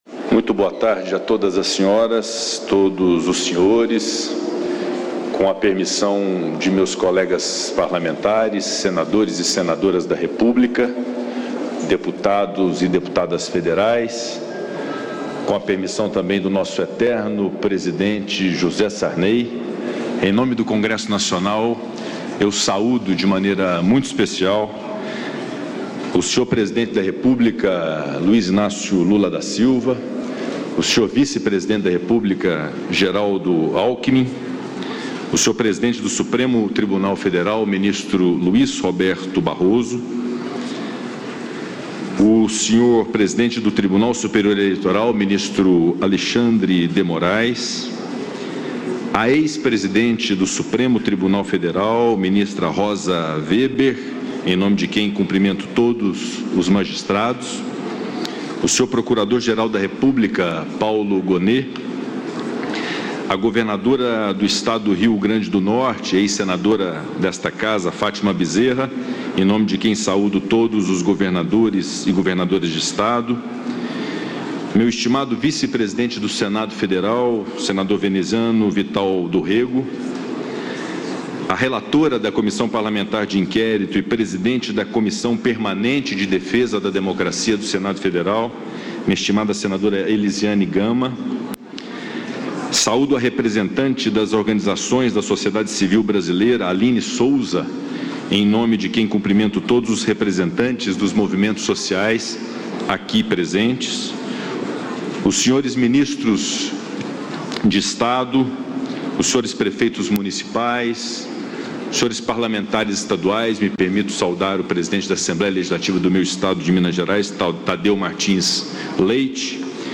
Acompanhe o pronunciamento do presidente do Congresso Nacional, senador Rodrigo Pacheco, durante o Ato Democracia Inabalada, realizado nesta segunda-feira (8), no Congresso Nacional, em Brasília (DF).